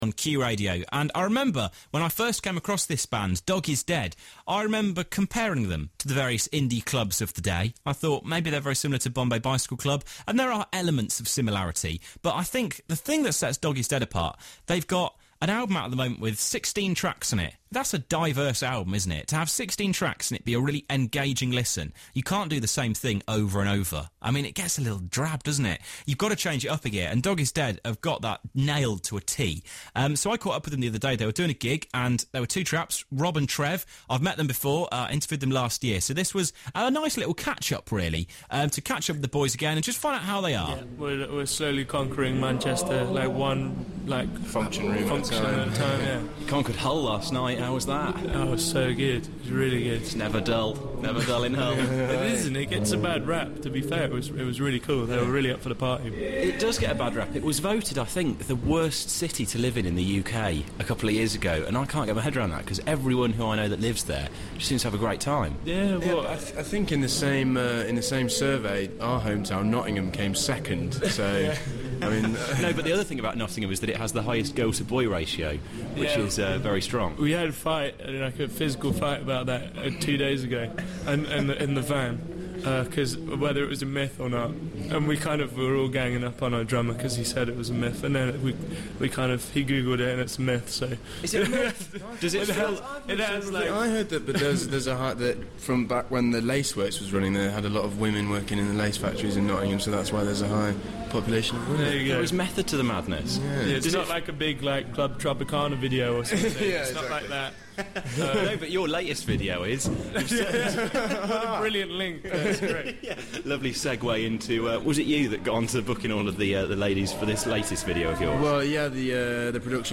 May13 - Dog Is Dead Interview - Q Now